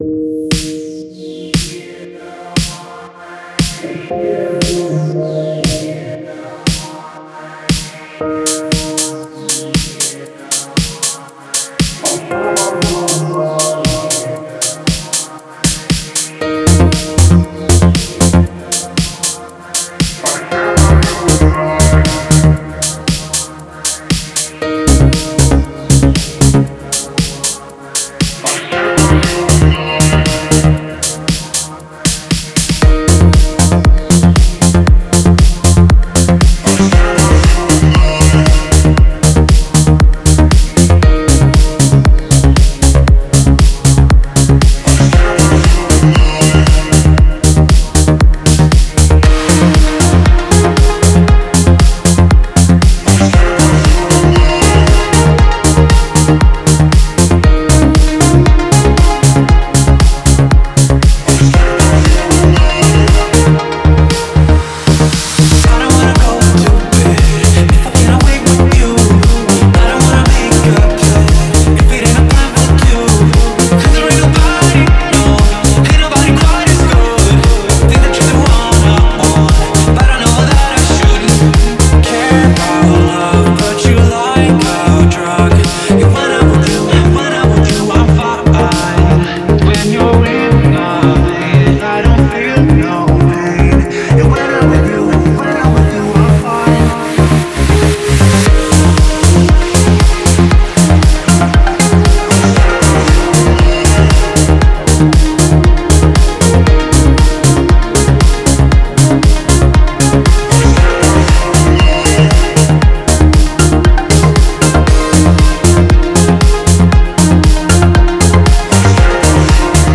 это трек в жанре deep house